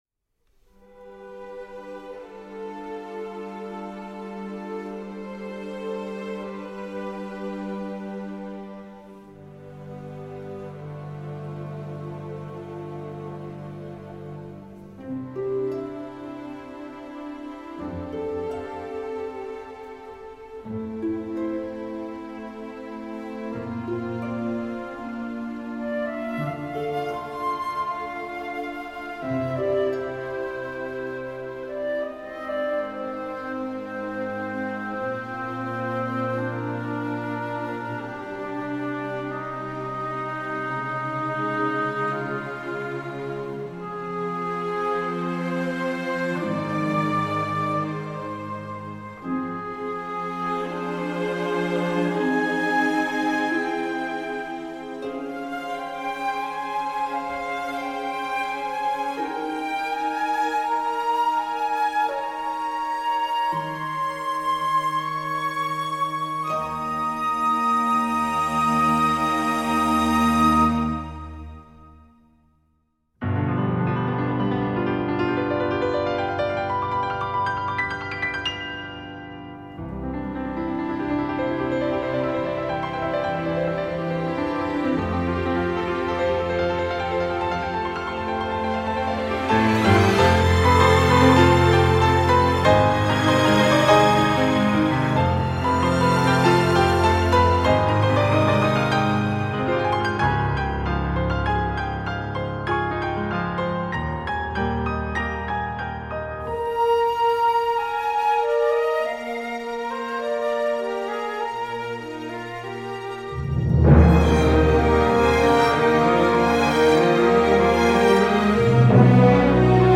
C’est orchestral, pas forcément thématique mais très enlevé.